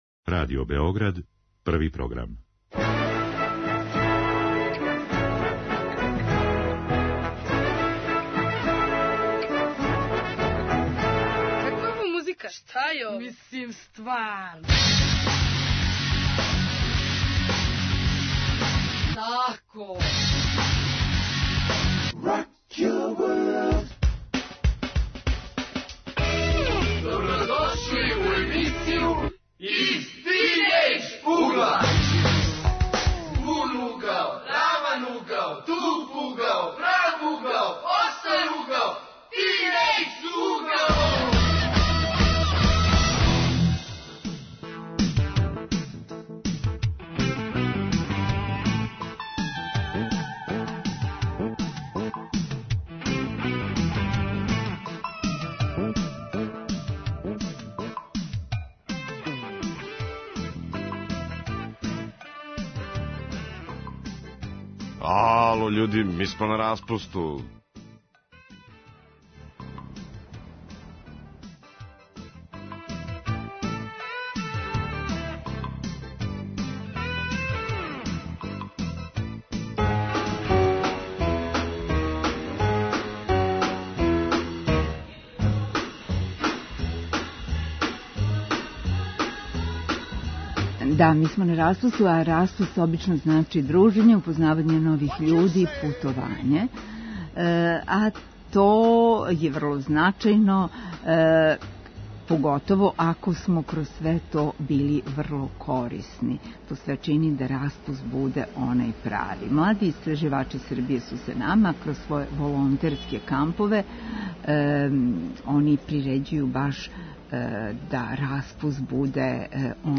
Лето у знаку волонтирања, дружења, упознавања... Гости - млади који свој распуст проводе у волонтерским камповима Младих истраживача.